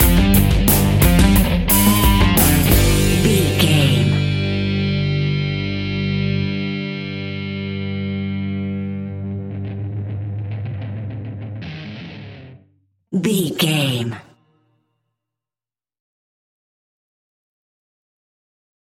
Powerful Rock Music Track Stinger.
Epic / Action
Aeolian/Minor
heavy metal
distortion
instrumentals
rock guitars
Rock Bass
Rock Drums
heavy drums
distorted guitars
hammond organ